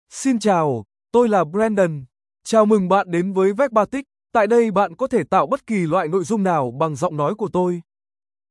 Brandon — Male Vietnamese (Vietnam) AI Voice | TTS, Voice Cloning & Video | Verbatik AI
BrandonMale Vietnamese AI voice
Brandon is a male AI voice for Vietnamese (Vietnam).
Voice sample
Listen to Brandon's male Vietnamese voice.
Male